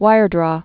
(wīrdrô)